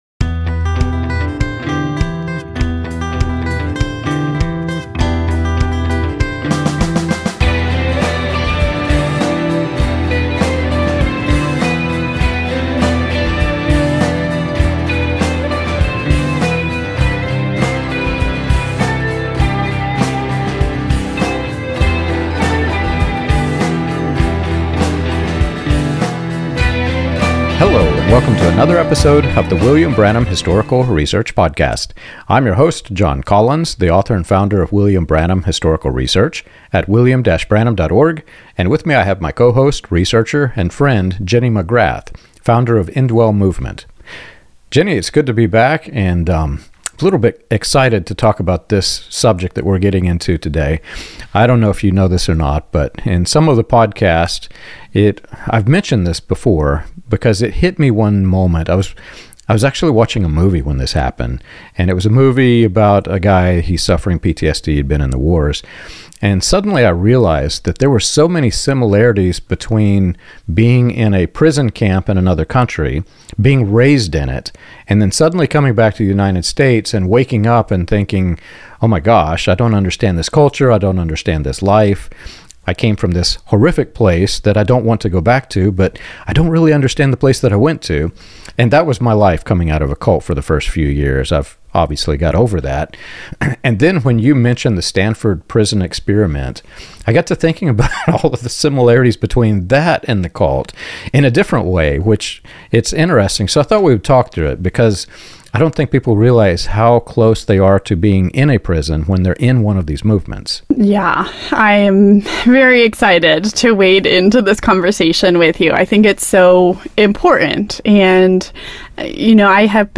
The conversation connects forced confession, information control, moral injury, and fear-based leadership to movements such as Branhamism and Youth With A Mission, showing how power structures reshape conscience, identity, and empathy. This episode offers clarity for survivors of spiritual abuse and practical insight into what healthy, non-coercive community actually looks like.